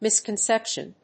音節mìs・concéption 発音記号・読み方
/ˌmɪskənˈsɛpʃən(米国英語), mɪskʌˈnsepʃʌn(英国英語)/